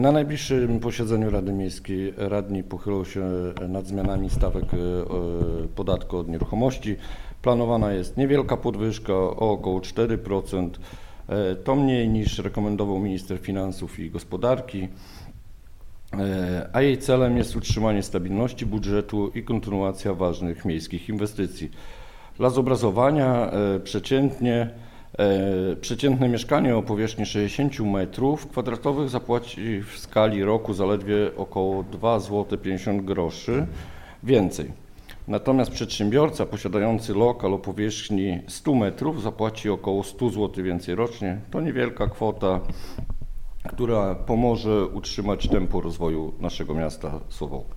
W planie jest podwyżka podatku od nieruchomości o około 4 proc. Projekt uchwały zakłada pozostawienie stawek od środków transportowych na dotychczasowym poziomie. O szczegółach mówił w Suwalskim Magazynie Samorządowym Kamil Lauryn, przewodniczący Komisji Finansowo-Budżetowej w Radzie Miasta Suwałki.